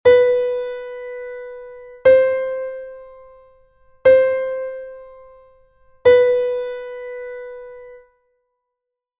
Exercise 3: high B-C diatonic semitone exercise.
semitono_diatonico_si-do.mp3